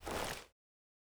bino_holster.ogg